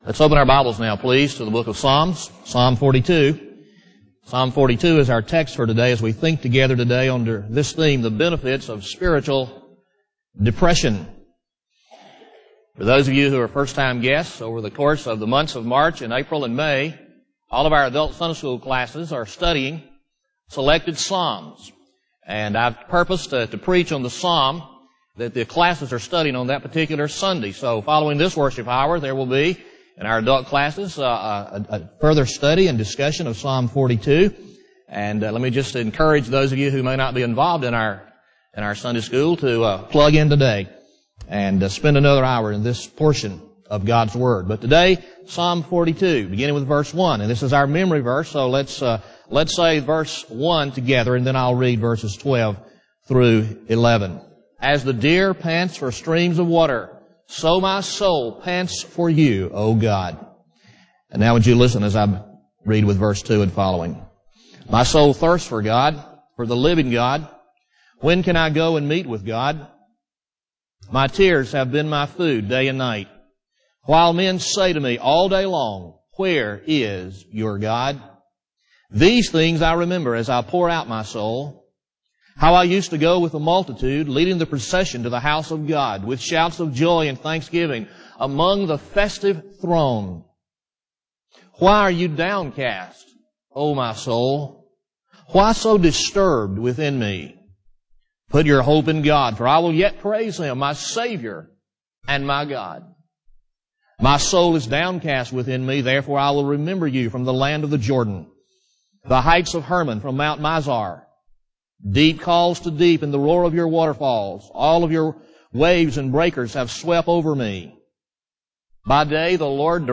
Lakeview Baptist Church - Auburn, Alabama
Sermon